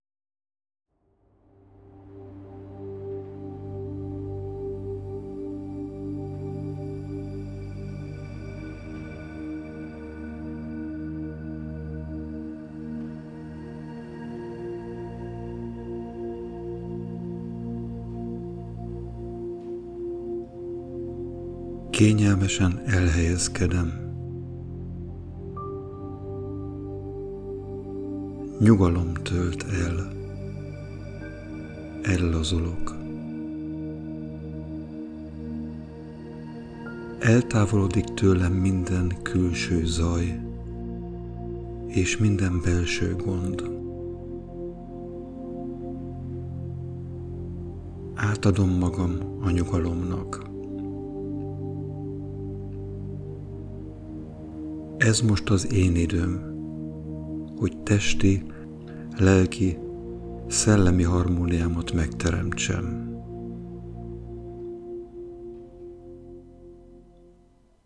Halk zenével
Autogen_trening_minta_halkabb_zenevel.mp3